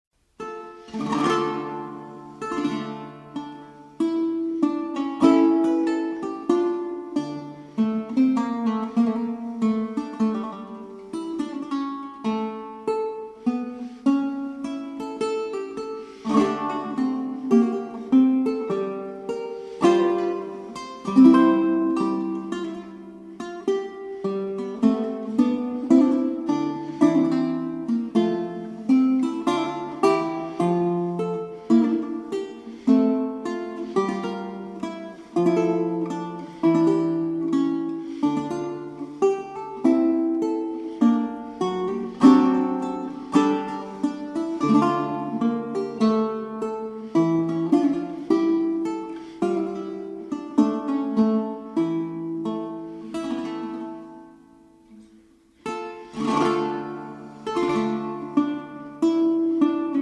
performed on baroque guitar.